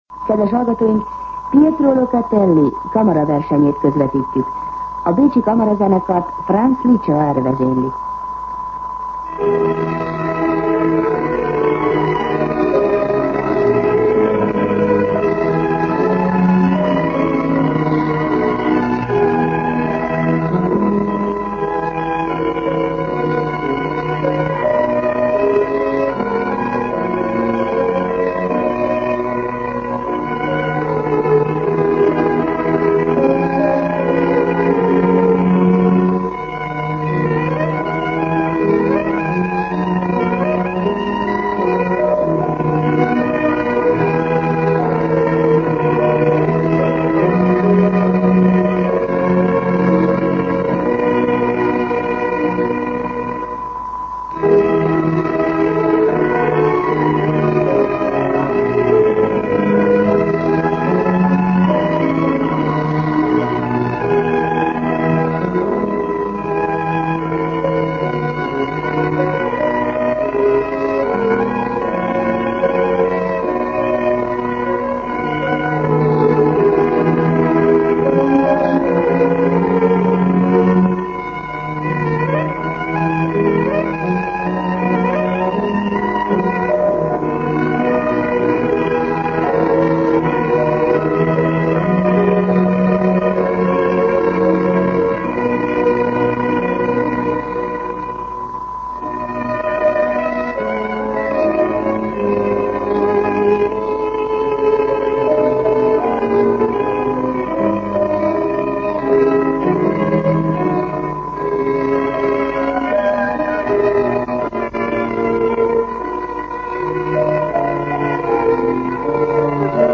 Zene
Itt a Szabad Európa Rádiója, a Szabad Magyarország Hangja.